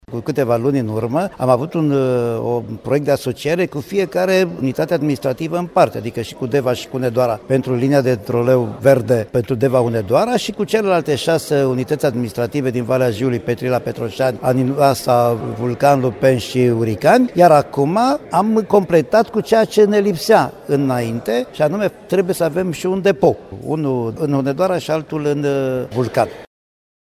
Acestea vor fi complet ecologice şi se vor realiza între oraşele Deva şi Hunedoara şi între cele şase oraşe din Valea Jiului, spune preşedintele Consiliului Județean Hunedoara, Mircea Moloţ.